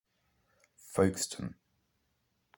Folkestone (/ˈfəʊkstən/
FOHK-stən) is a coastal town on the English Channel, in Kent, south-east England.